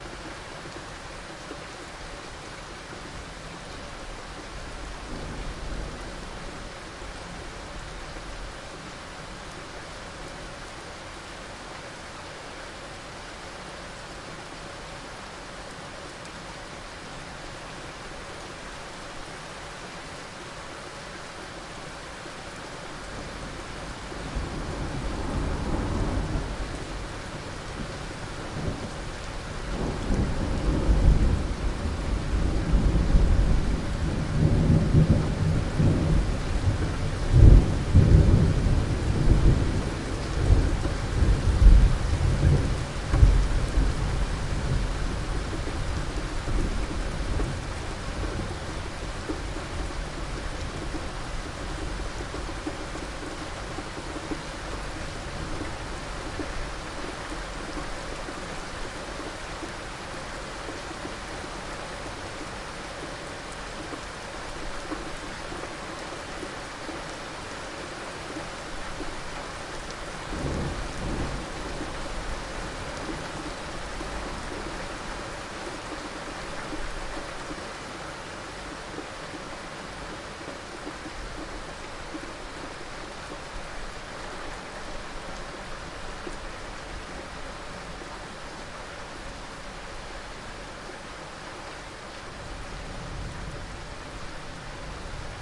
描述：2012年7月29日，严重的雷雨天气袭击了贝塞尔。它涉及强风和小冰雹。它还产生了一些近距离的闪电。我试图用我的SONY STEREO DICTAPHONE来记录它，
标签： 风暴 暴雨 严重的 闪电 雷暴 现场记录
声道立体声